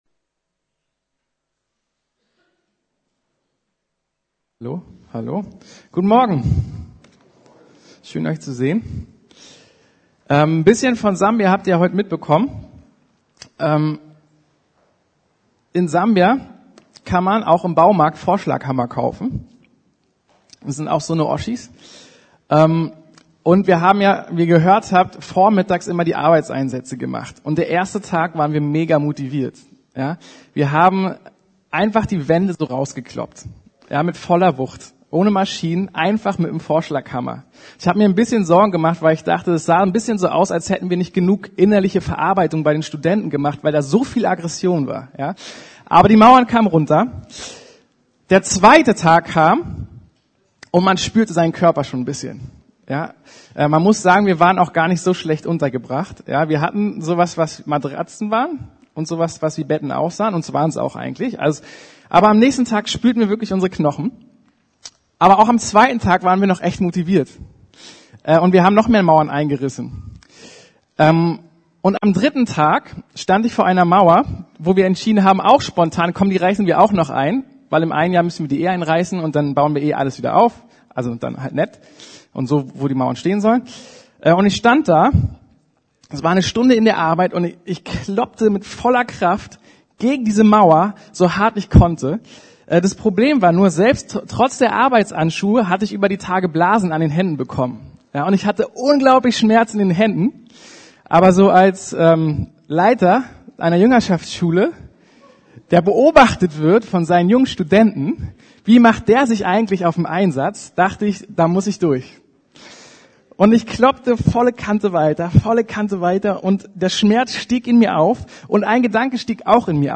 ~ Predigten der LUKAS GEMEINDE Podcast